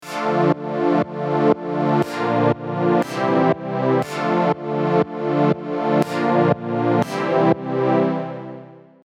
Here is the Sidechained synth line.
As you can hear in the examples, this technique is very audible.
Sidechained_Synth.mp3